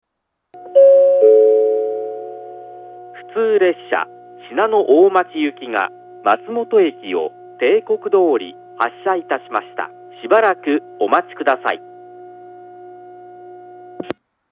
発車ベルはありませんが、接近放送があります。
１番線下り松本駅発車案内放送 普通信濃大町行の放送です。